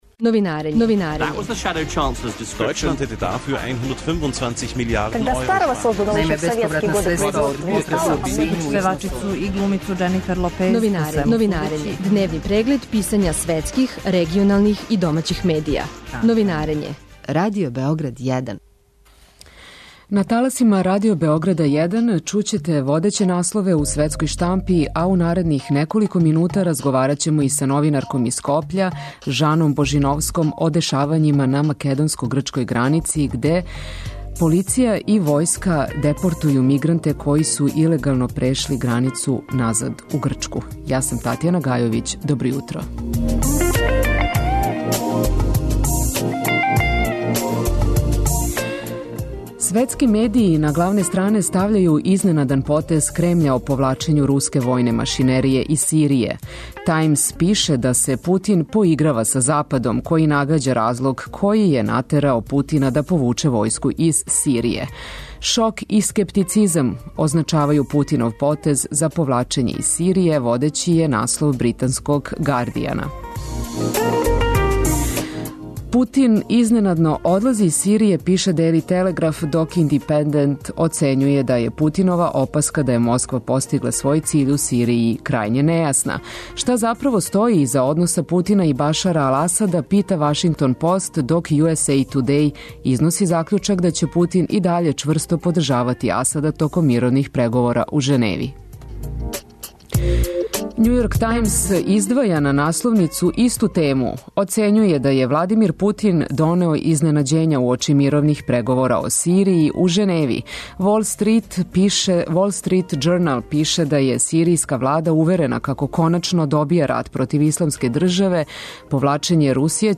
Новинарење